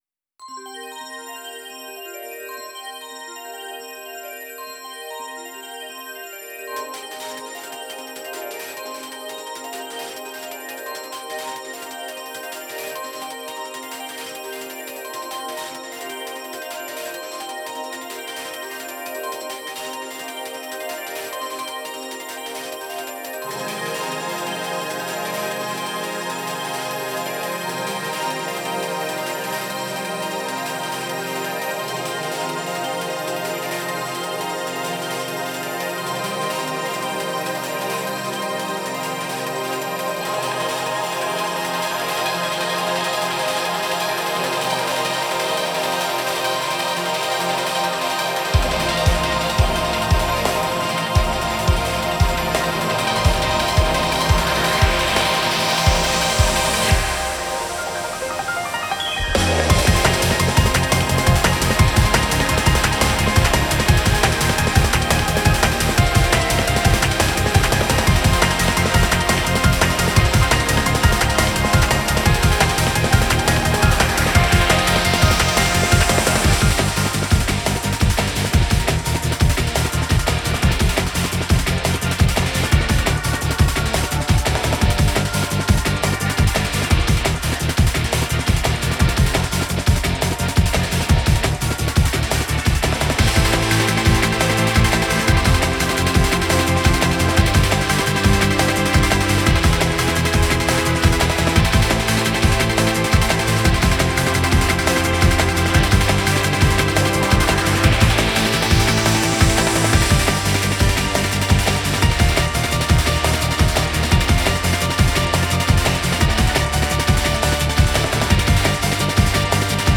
タイアップ・カラオケ